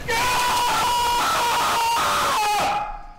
yelling-4